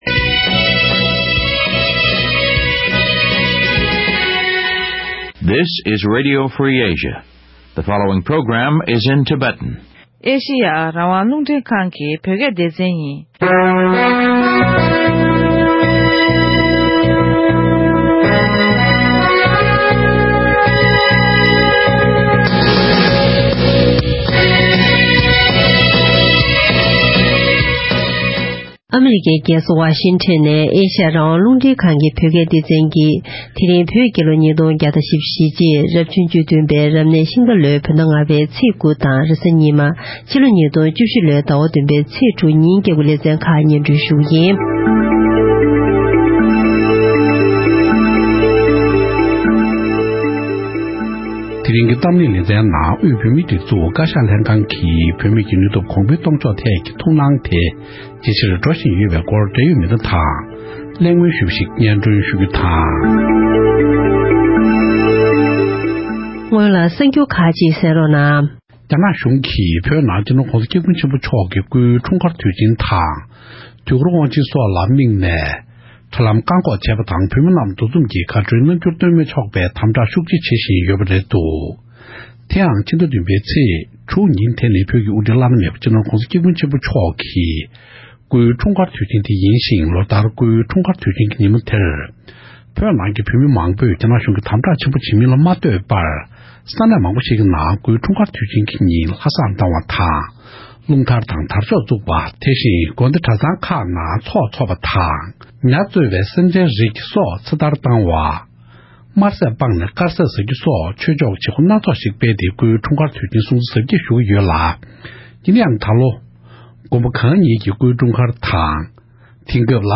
འབྲེལ་ཡོད་མི་སྣ་ཁག་ཅིག་དབར་གླེང་མོལ་ཞུས་པ་ཞིག་གསན་རོགས་གནང་།།